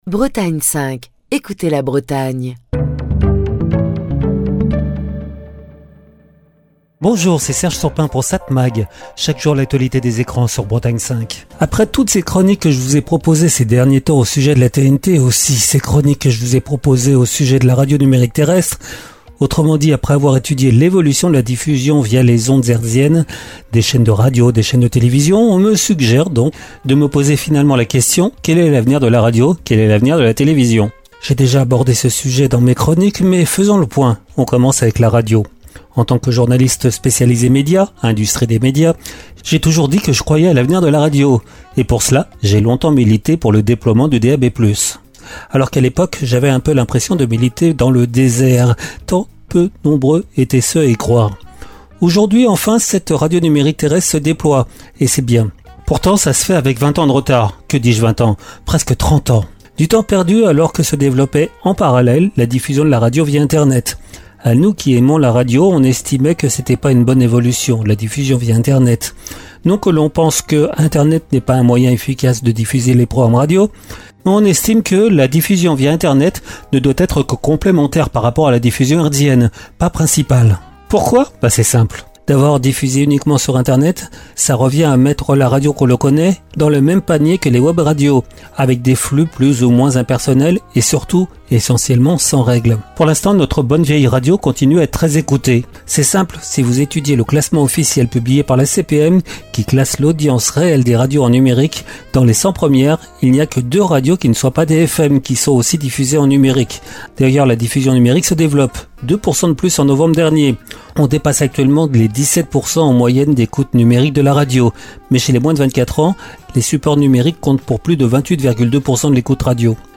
Chronique du 24 mars 2025. La question est vaste... reste que quels que soient les moyens de diffusion, ce sont les contenus qui font le succès de la radio, succès qui ne se dément pas.